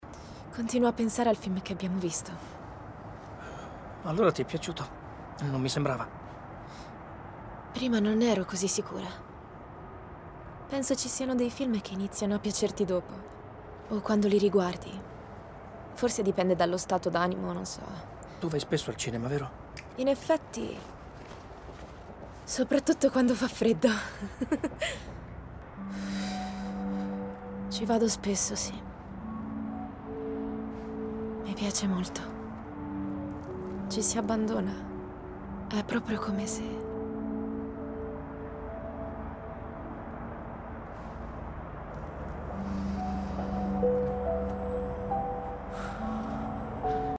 in cui doppia Noée Abita.